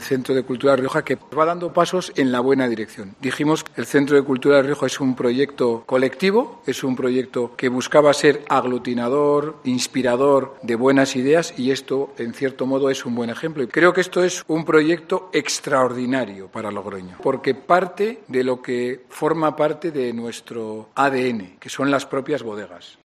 Conrado Escobar, alcalde de Logroño